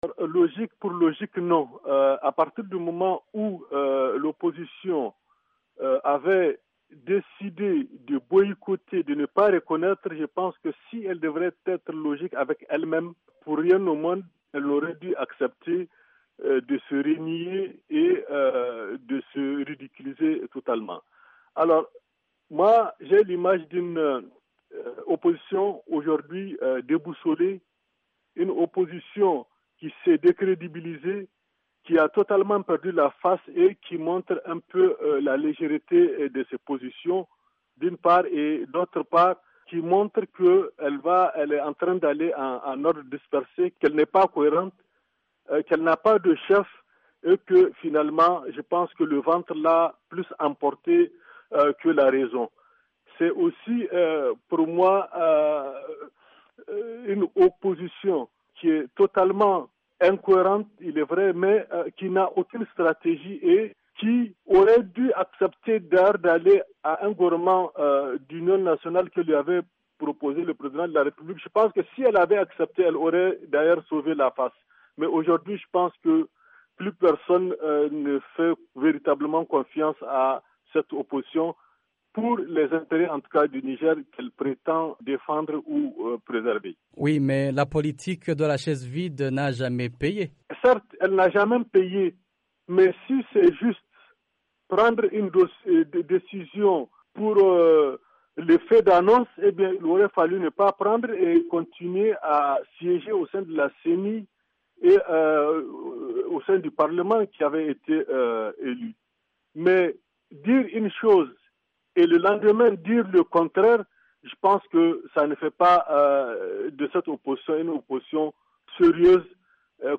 Selon un analyste politique joint à Niamey par VOA Afrique, "le ventre" a primé sur "la raison".